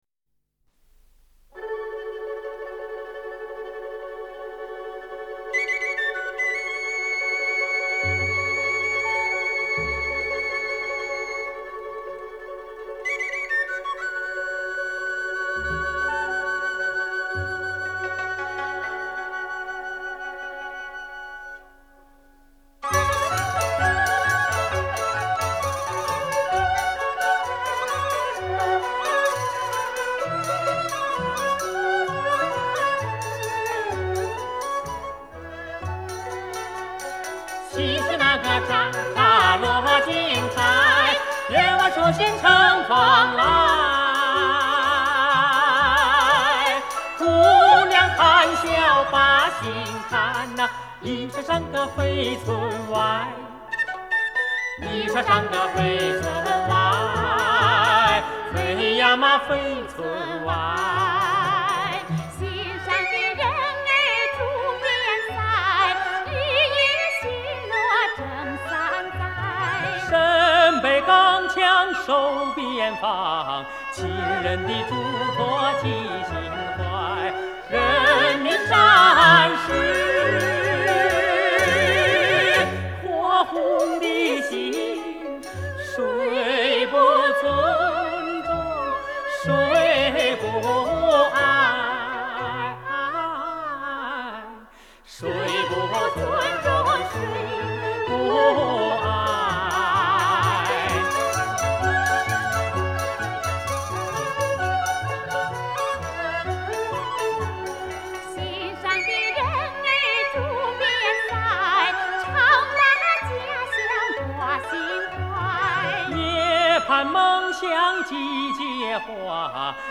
质朴的情感，奔放的激情，难忘的旋律。